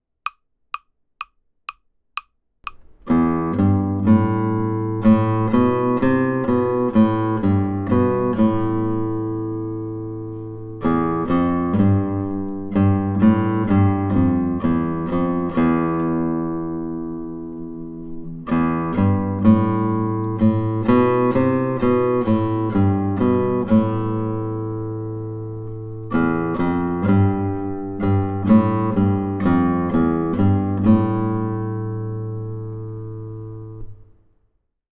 Melody only